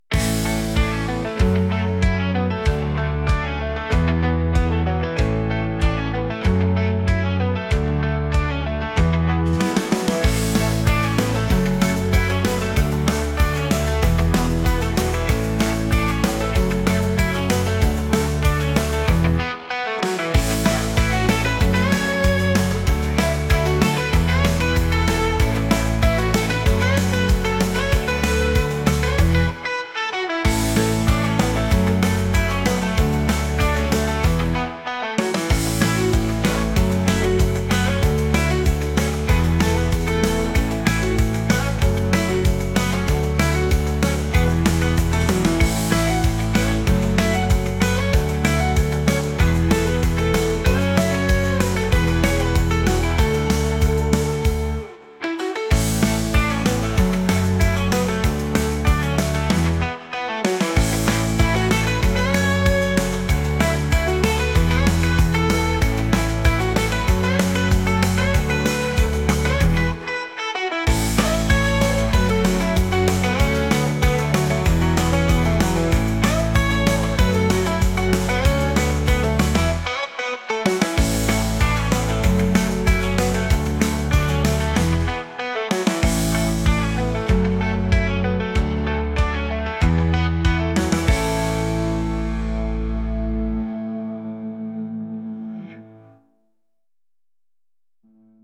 スーパーマーケットに買い物に来ているようなギターの曲です。